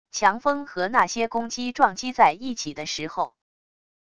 强风和那些攻击撞击在一起的时候wav音频